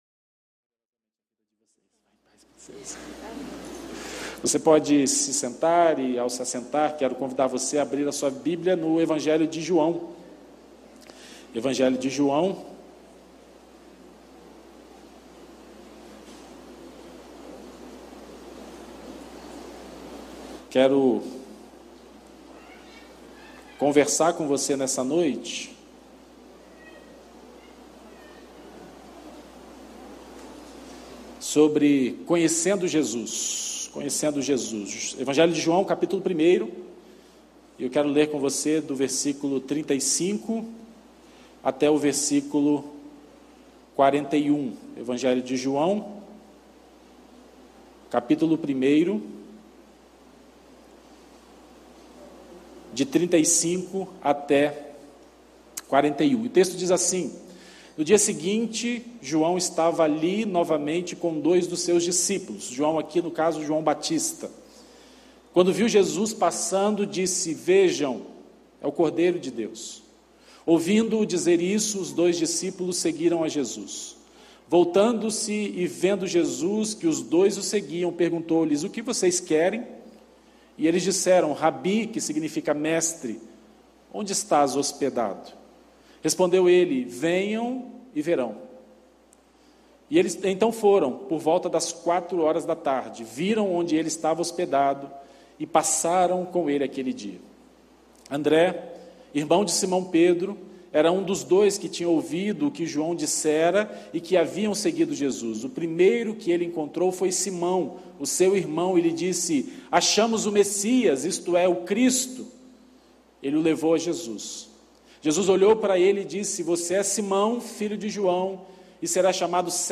Mensagem apresentada